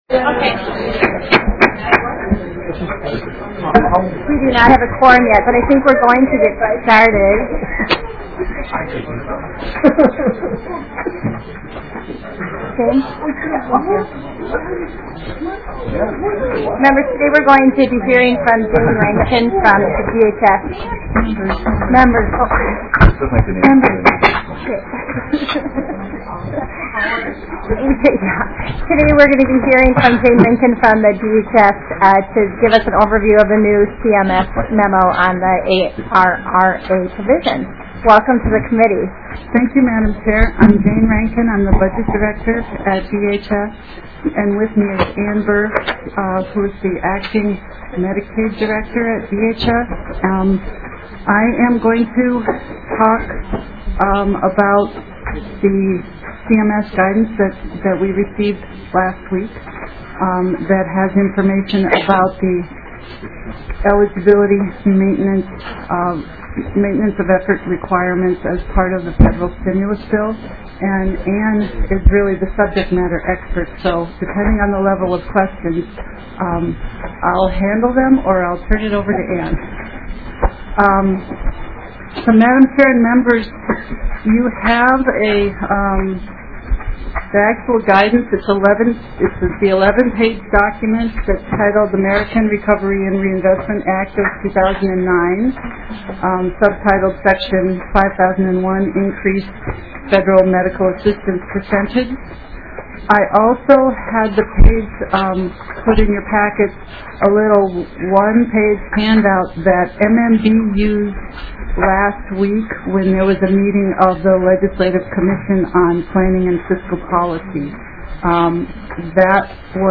02:27 - Gavel. 02:53 - Department of Human Services update on federal stimulus provisions. 31:24 - HF8 (Simon) Minnesota False Claims Act established. 1:30:41 - Testimony on governor's supplemental budget proposal for General Assistance Medical Care (GAMC).